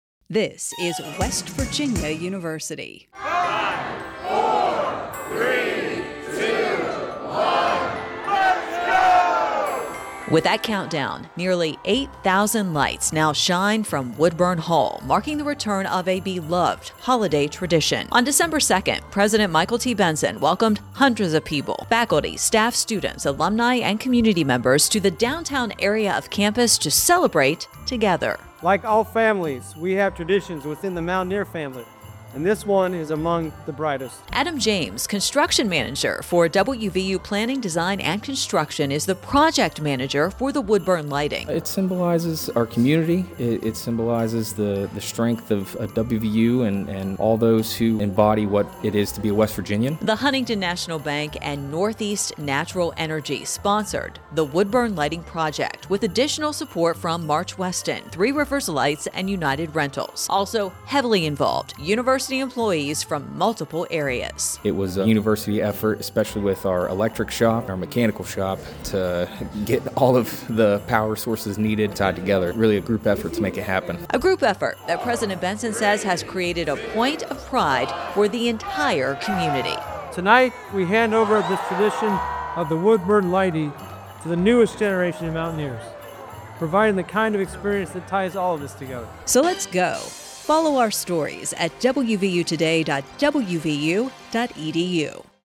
Woodburn Lighting radio spot
woodburn-lighting-radio-spot.mp3